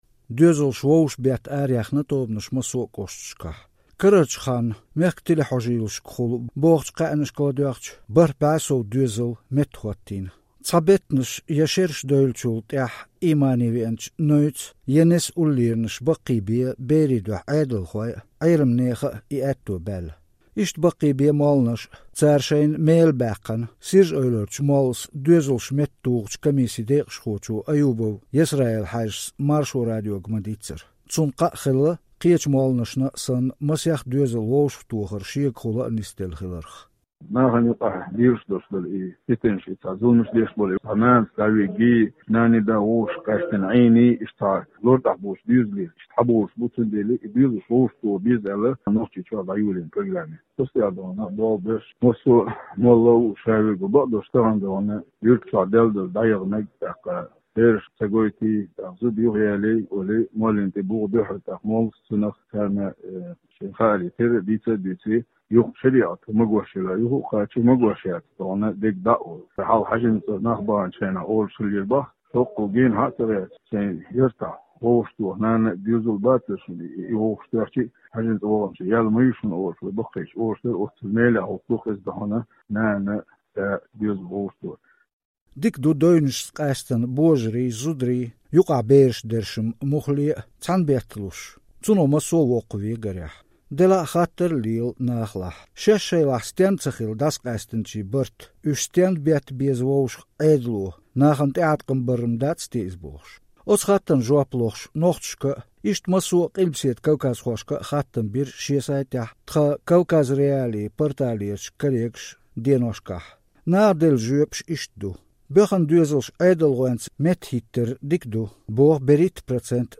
Экам тема хIара хиларна, Маршо Радионо девзар доцуш хийцина къамелдечийн аьзнаш. Цхьана юьртарчу зудчо дуьйцу кху муьрехь иккхинчу дилеммах лаьцна.
Оцу зудчуьнга ладоьгIначу кхечу зудчо хьоьху шен кхетамехь муха ерзо еза ситуаци.